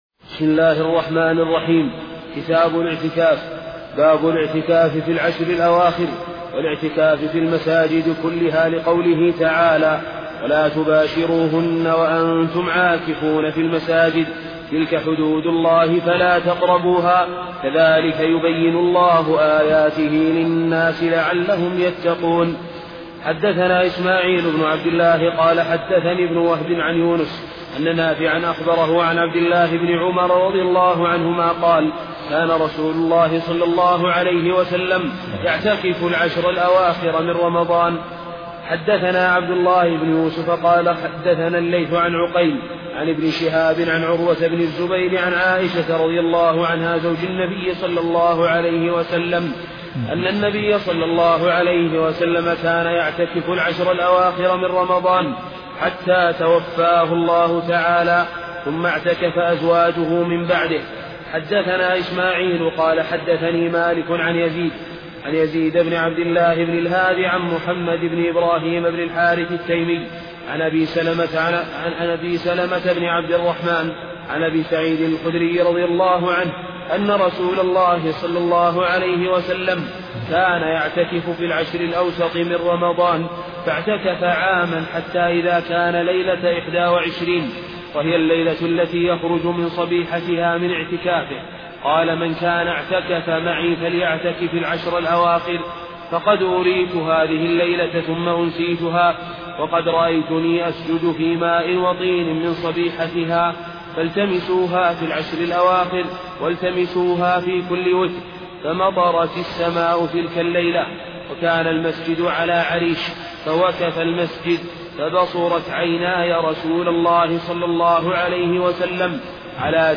محاضرة صوتية نافعة، وفيها شرح الشيخ عبد العزيز بن عبد ا